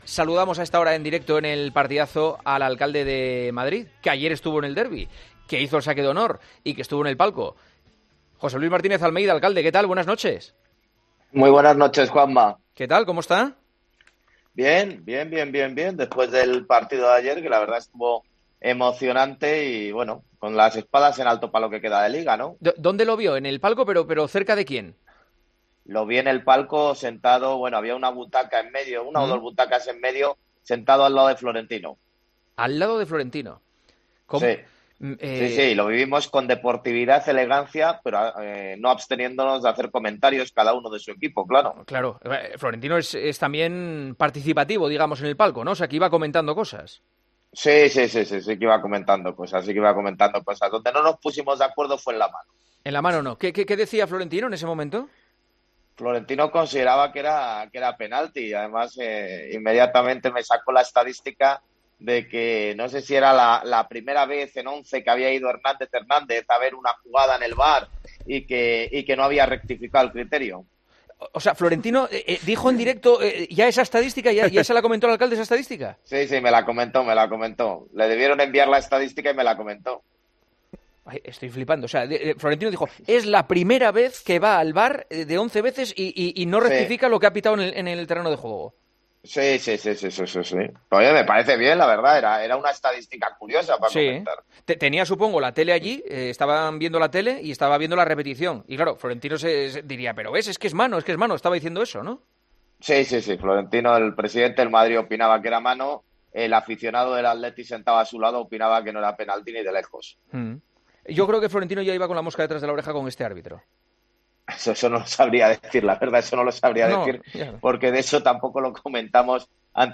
AUDIO: Entrevistamos al alcalde de Madrid para hablar del empate en el derbi del domingo entre el Atleti y el Real Madrid.